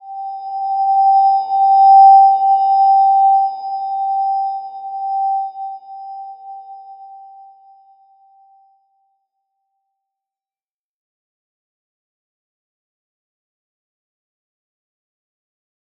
Slow-Distant-Chime-G5-mf.wav